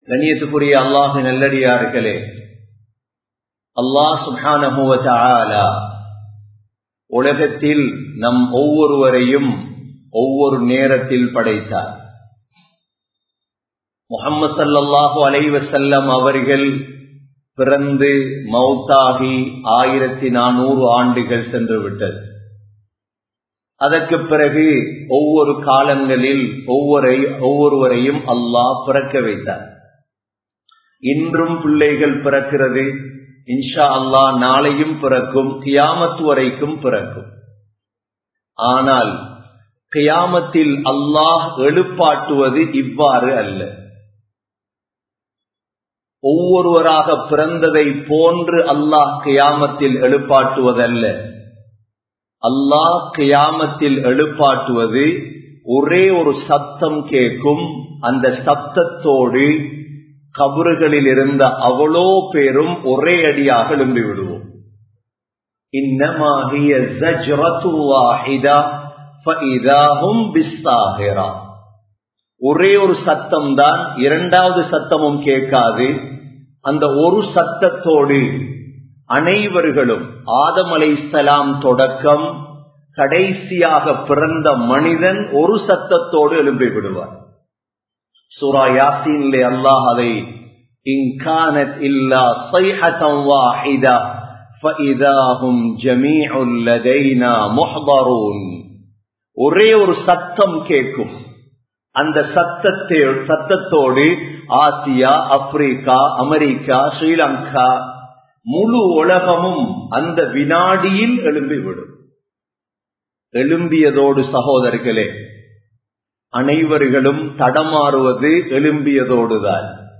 07 Vahaiyaana Narahangal (07 வகையான நரகங்கள்) | Audio Bayans | All Ceylon Muslim Youth Community | Addalaichenai
Colombo 11, Samman Kottu Jumua Masjith (Red Masjith)